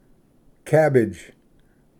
pronounces the English name for ᏧᎦᎾᏕᎾ/ cabbage